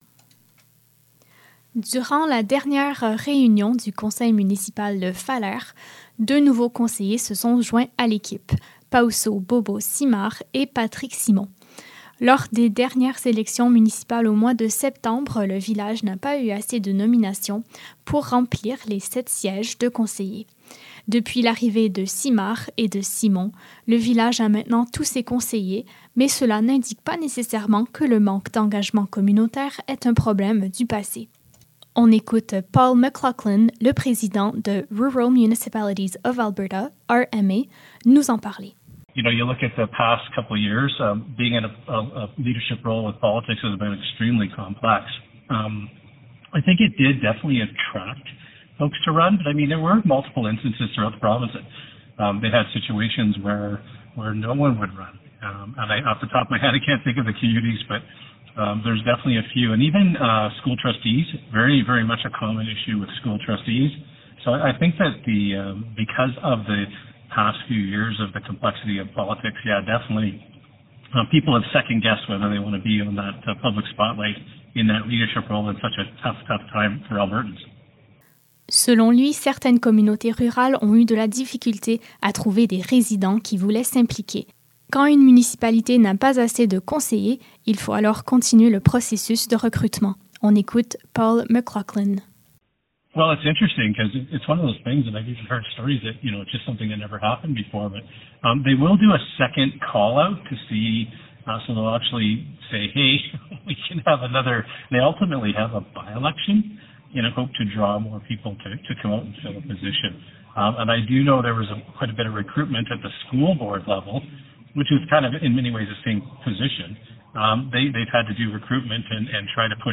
Reportage-Nouveaux-conseillers-Falher.mp3